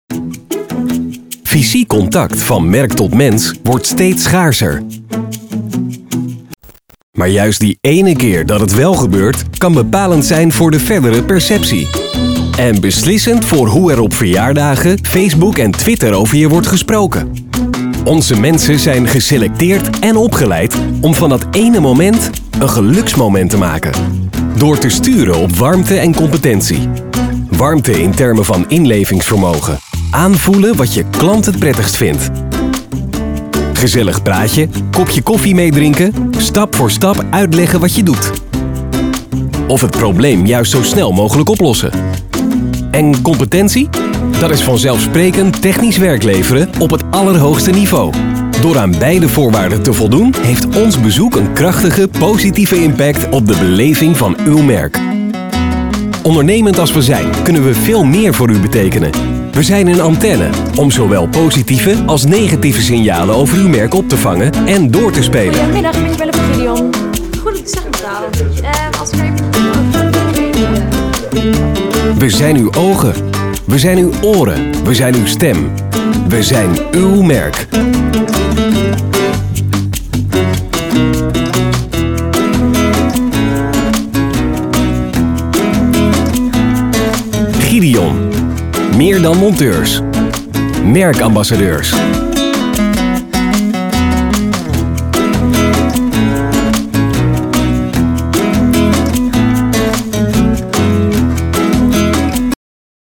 Dutch, Nieuwe Nederlandse voiceover, Commercial voiceover, new dutch voice talent
Sprechprobe: eLearning (Muttersprache):